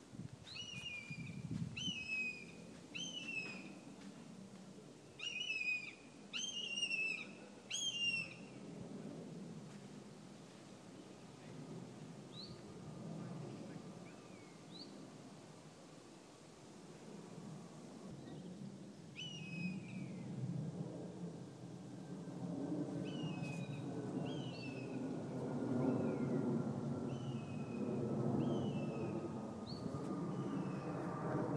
Buzzard (and A jumbo jet)